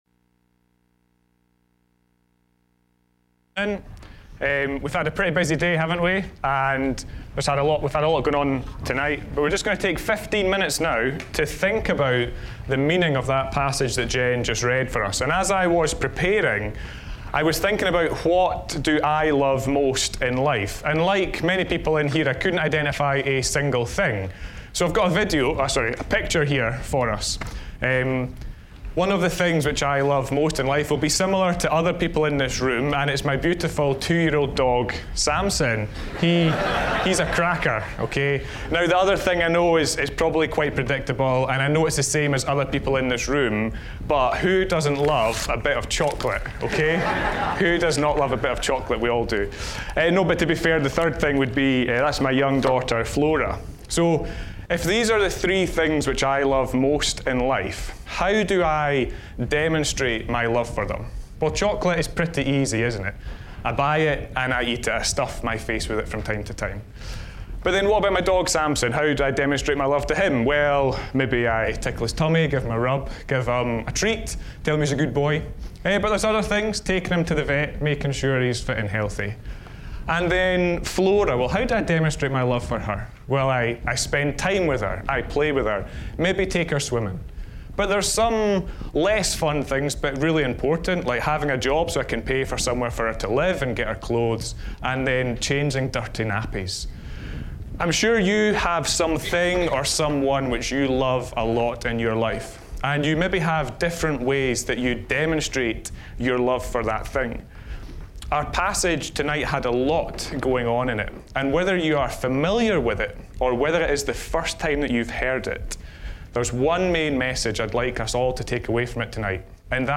At Sports Plus 2022 the evening meetings focused on the theme 'The King is Here.' This series focuses on the life, death and resurrection of King Jesus, as told in the gospel account written by Matthew.